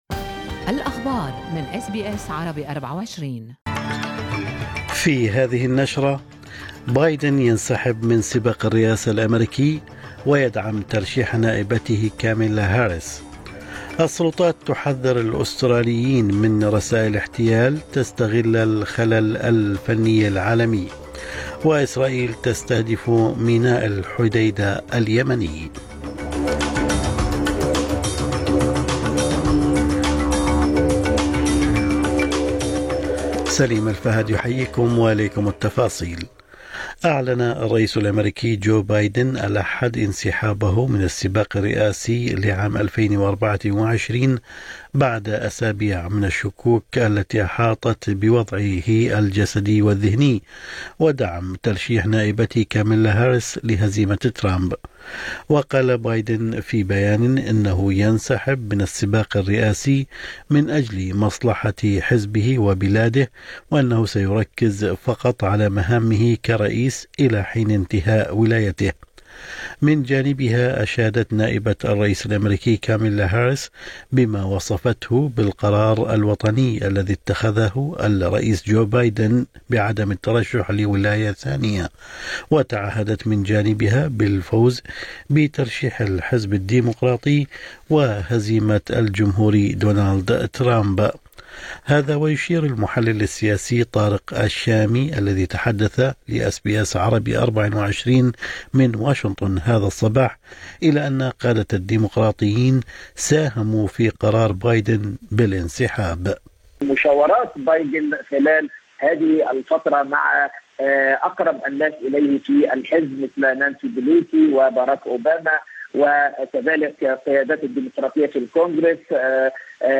نشرة أخبار الصباح 22/7/2024